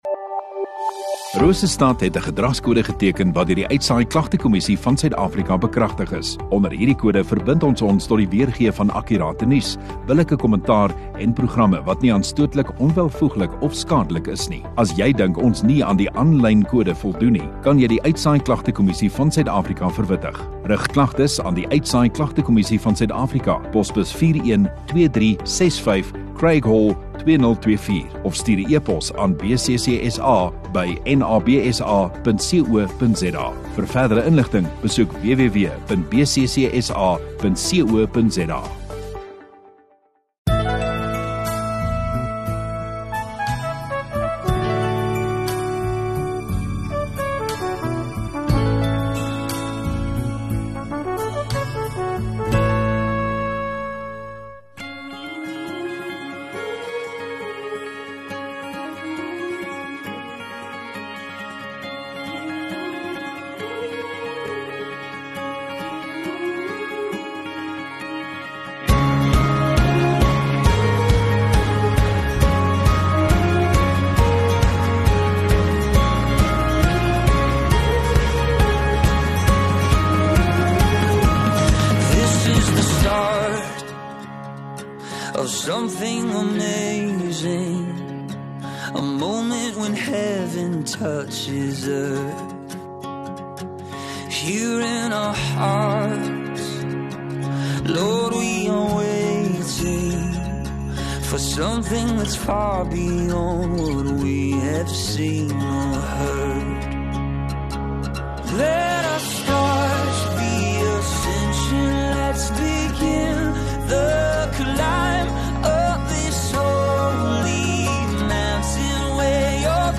24 Nov Sondagoggend Erediens